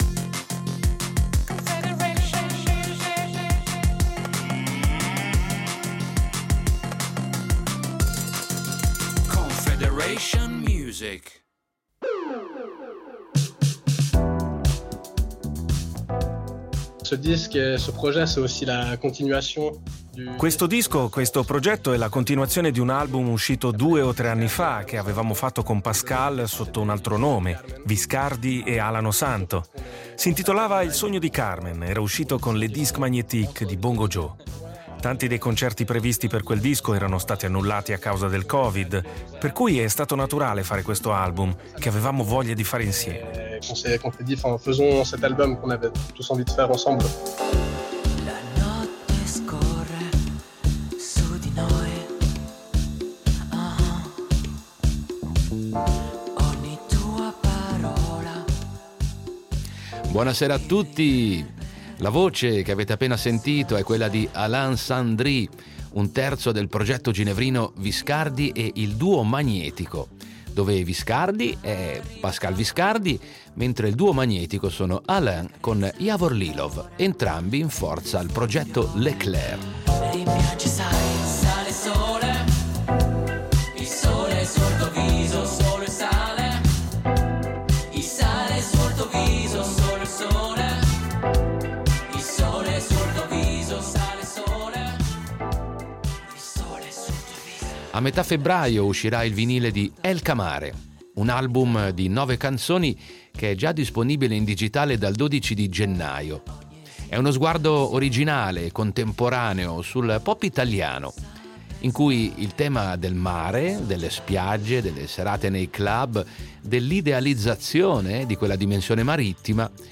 È una fotografia lirica e musicale da ballare, assolutamente originale, piena di personalità e qualche riferimento al dialetto napoletano.